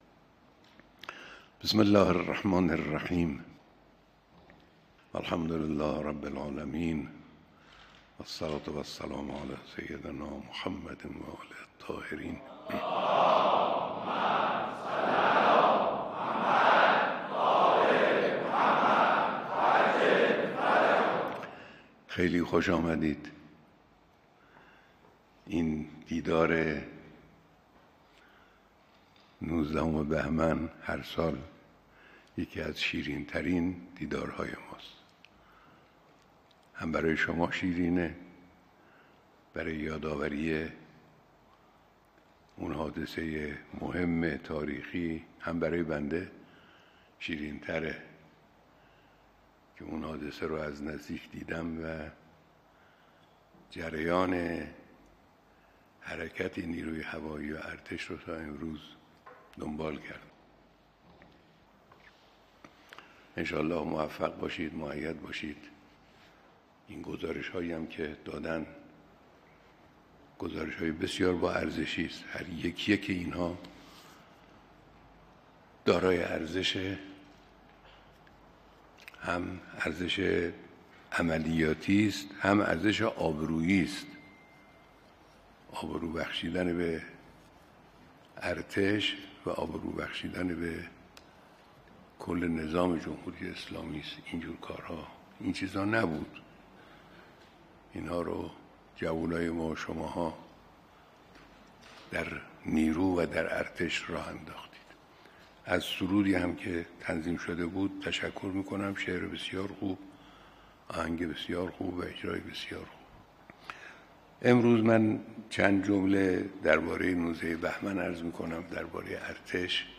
همزمان با ۱۹ بهمن، سالروز بیعت تاریخی جمعی ازهمافران با امام خمینی(ره) در سال ۱۳۵۷، جمعی از فرماندهان و کارکنان نیروی هوایی و پدافند هوایی ارتش صبح امروز با رهبر انقلاب اسلامی دیدار کردند. بیانات کامل رهبر معظم انقلاب در این دیدار را می‌شنوید.
بیانات رهبر انقلاب در دیدار کارکنان و فرماندهان نیروی هوایی